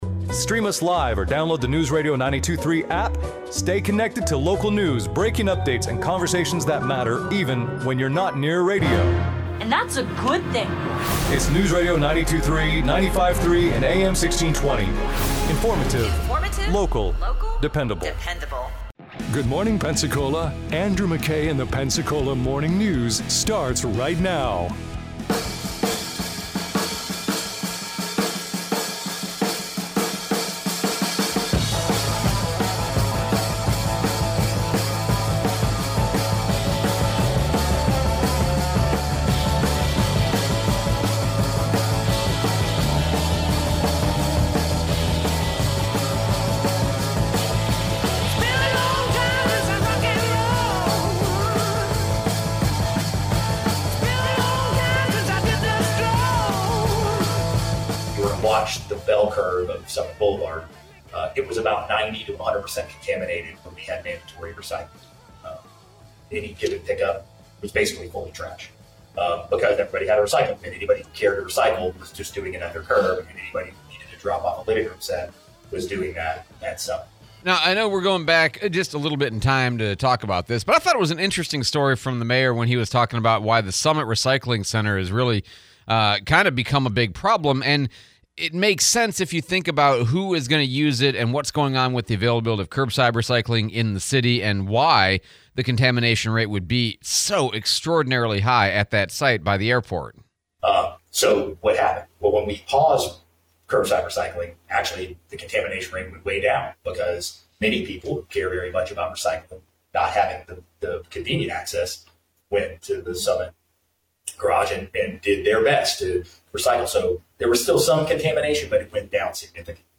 Pensacola curbside recycling, Mayor DC Reeves interview